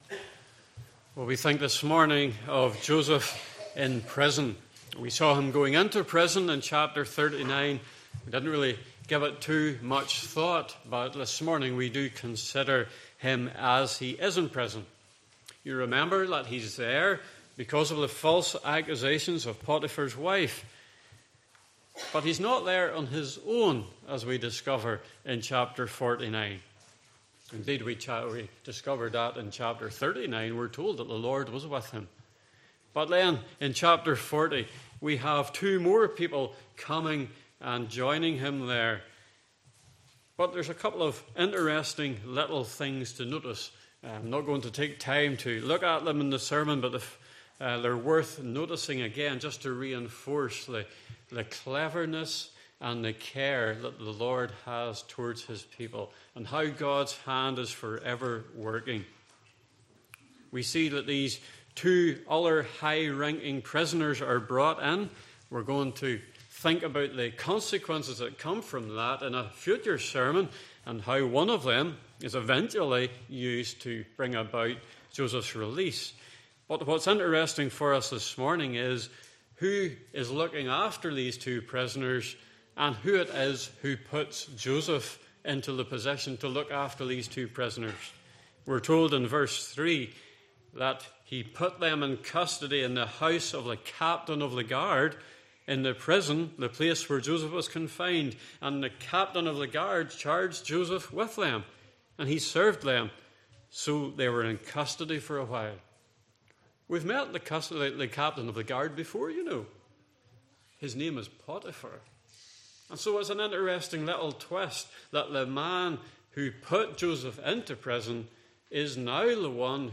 Passage: Genesis 40:1-23 Service Type: Morning Service